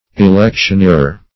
Electioneerer \E*lec`tion*eer"er\, n. One who electioneers.